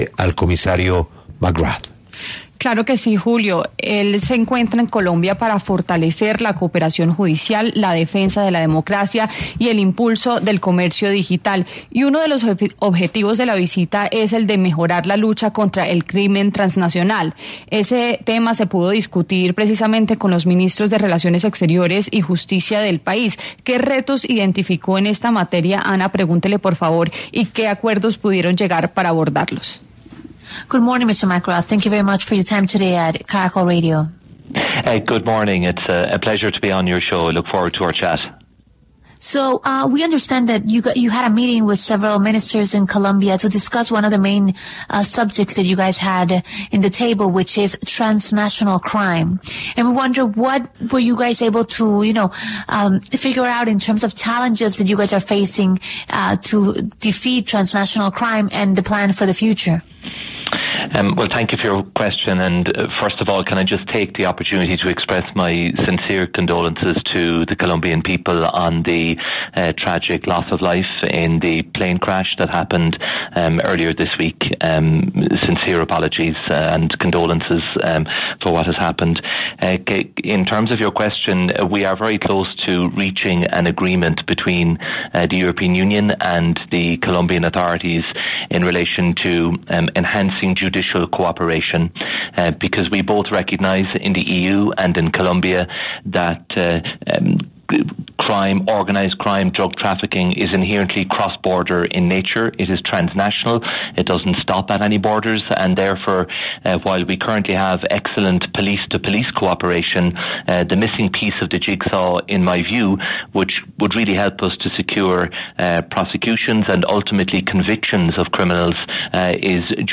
El comisario de la Unión Europea, Michael McGrath, evidenció en 6AMW cómo avanzan los esfuerzos con Colombia por combatir el crimen transnacional.